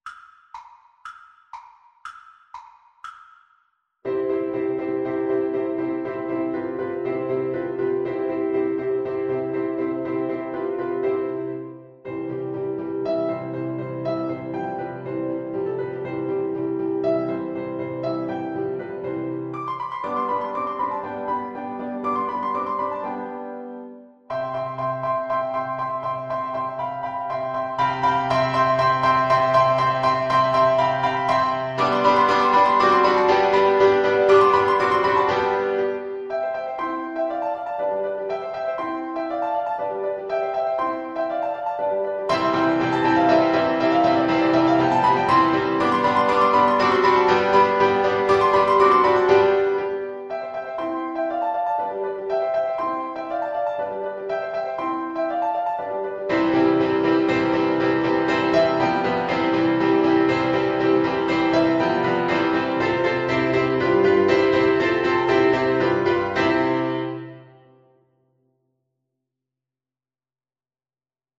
Play (or use space bar on your keyboard) Pause Music Playalong - Piano Accompaniment Playalong Band Accompaniment not yet available reset tempo print settings full screen
2/4 (View more 2/4 Music)
C major (Sounding Pitch) G major (French Horn in F) (View more C major Music for French Horn )
Classical (View more Classical French Horn Music)